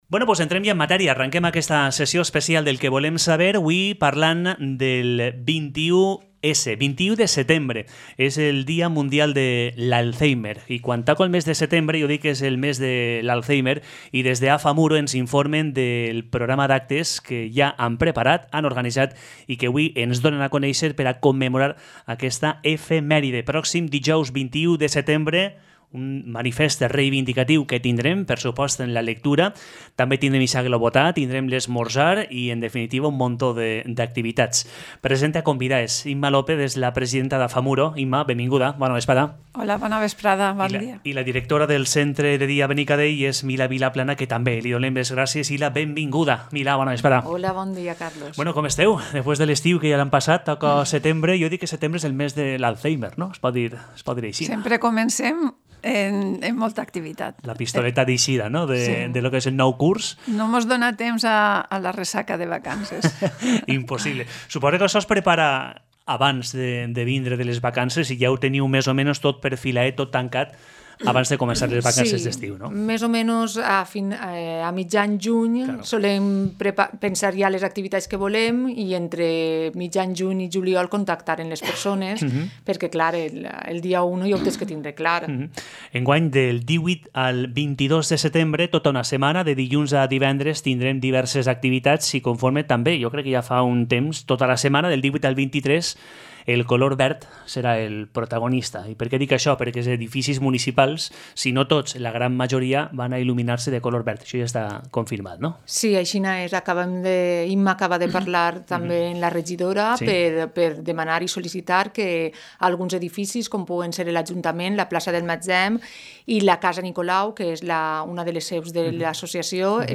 ENTREV-AFA-MURO.mp3